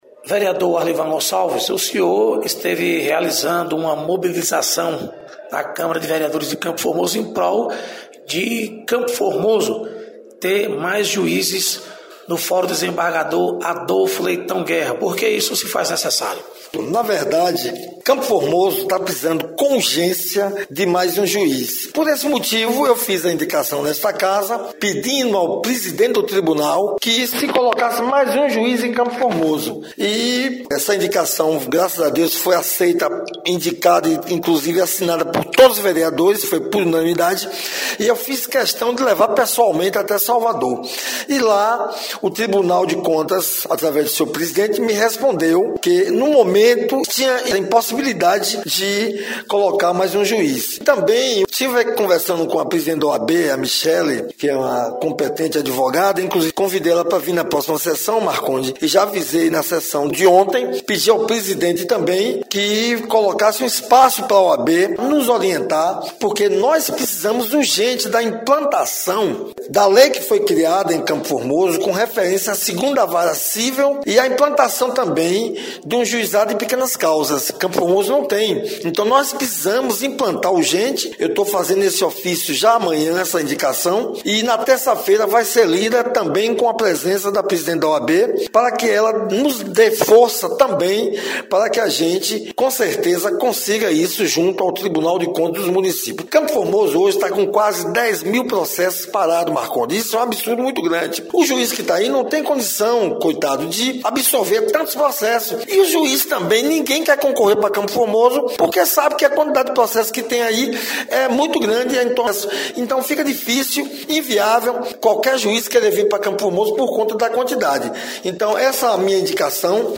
Reportagem- Vereadores